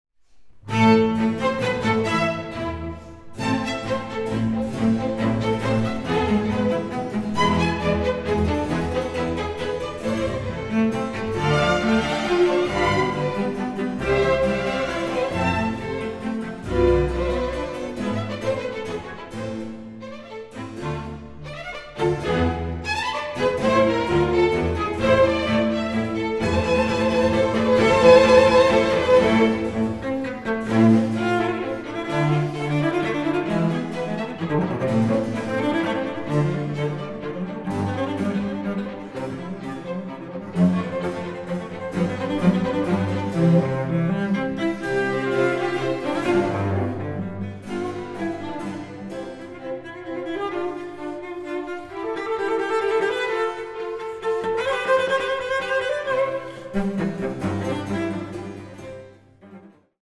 Cello
Klavier